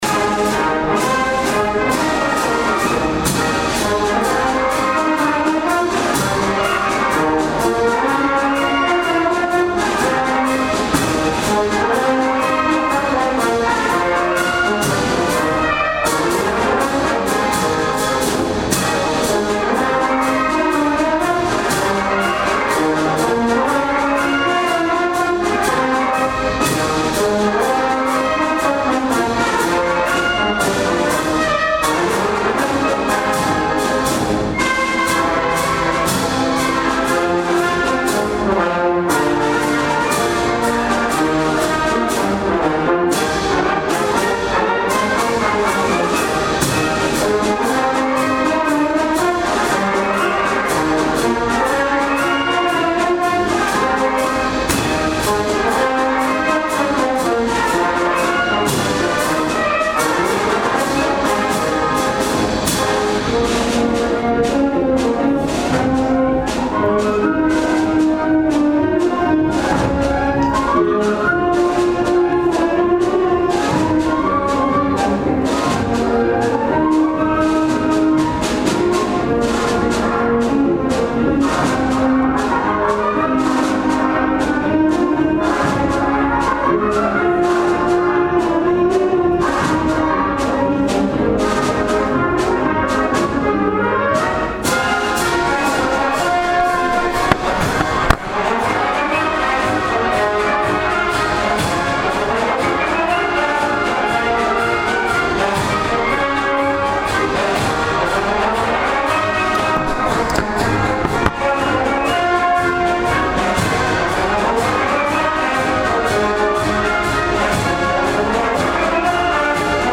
The third and final indoor concert for the 2025 season by the Karl L. King Municipal Band was held on Sunday afternoon, April 27th at 3:30 p.m. in the Fort Dodge Middle School Auditorium.
The final tune on the program was the Karl King march The Ohio Special.